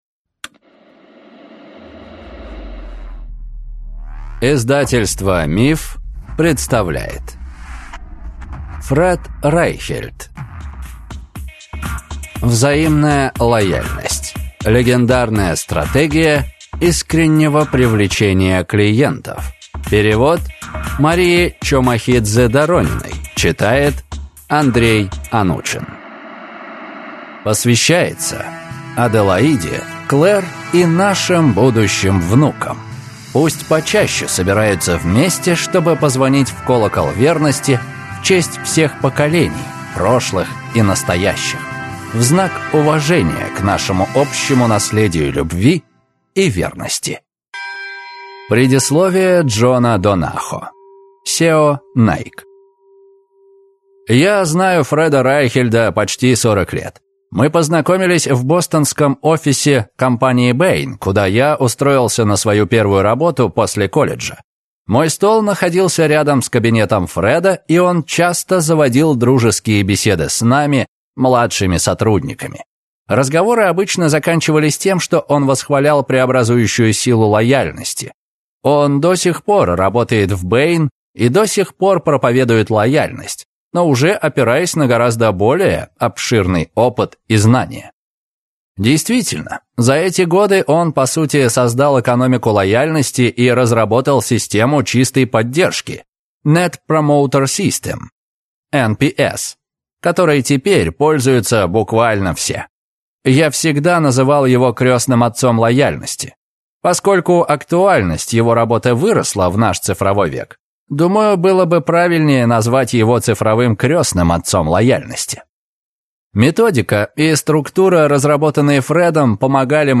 Аудиокнига Взаимная лояльность. Легендарная стратегия искреннего привлечения клиентов | Библиотека аудиокниг
Прослушать и бесплатно скачать фрагмент аудиокниги